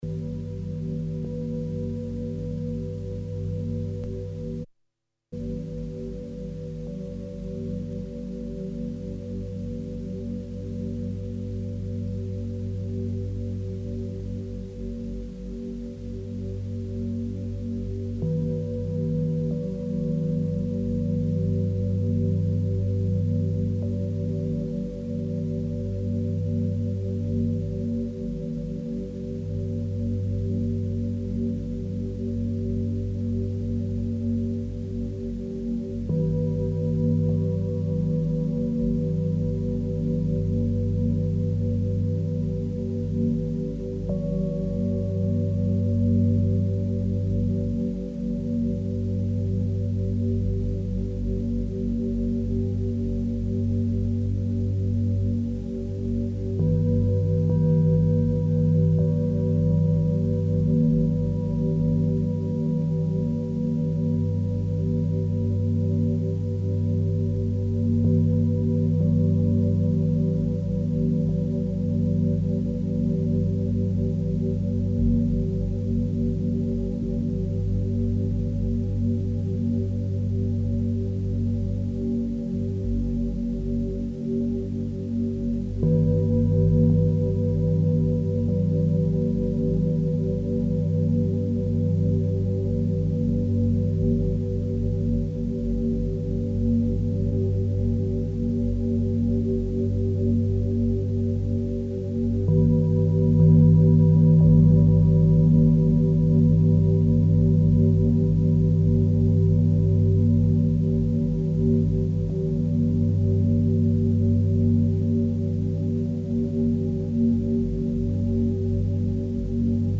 GongMeditaion.mp3